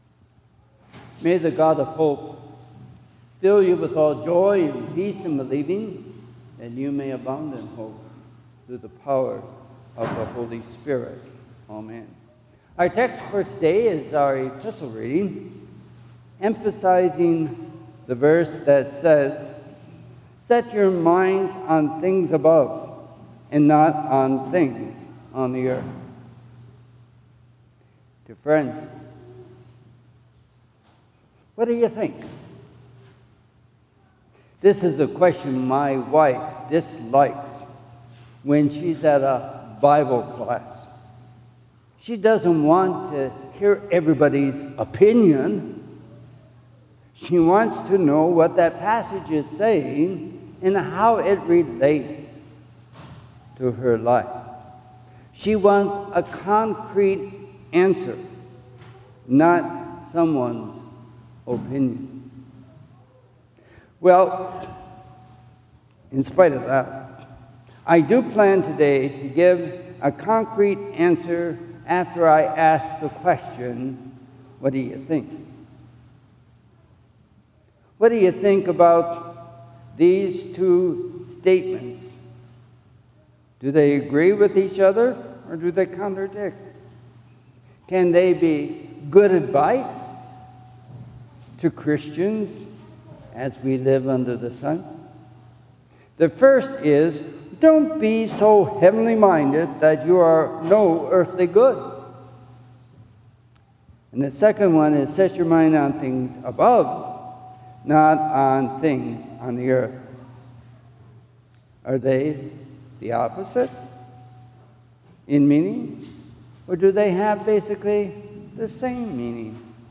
ZION-SERMON_AUGUST_3_2025.mp3